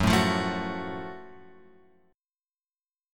F#mM7 chord {2 0 x 2 2 1} chord